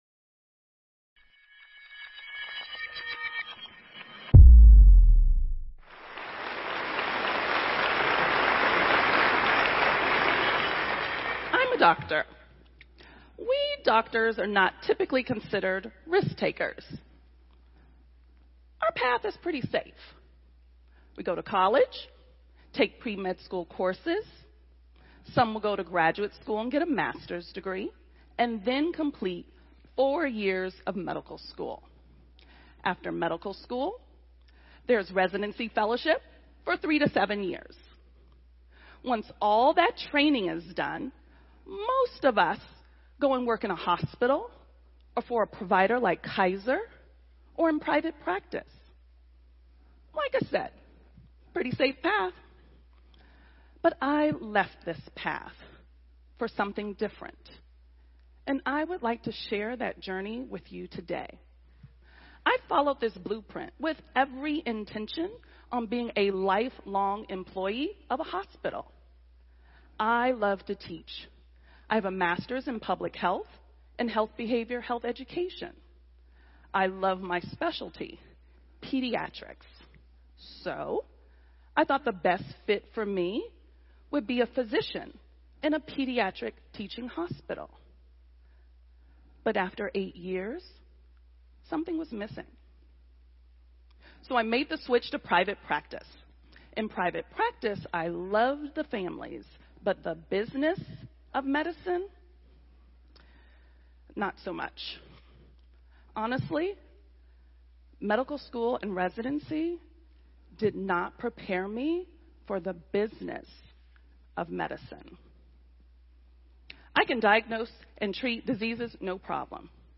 TEDxFoggyBottom